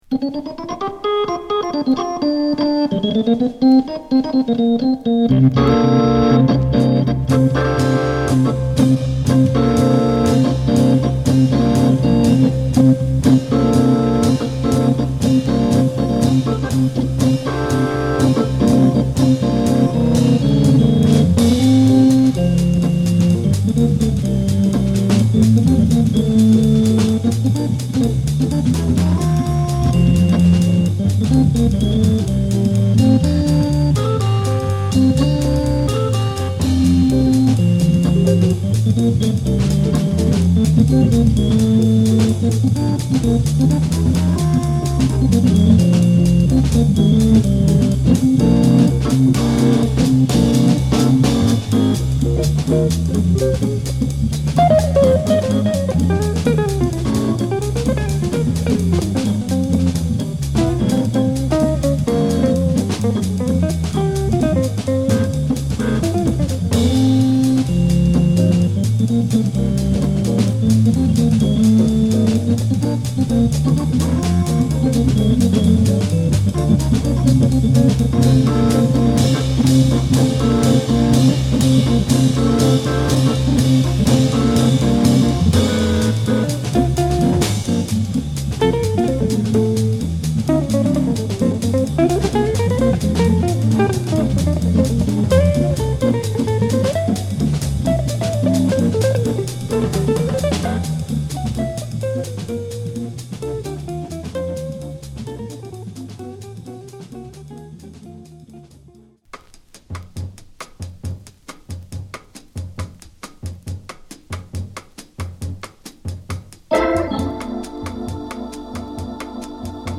ナイス・オルガンジャズです！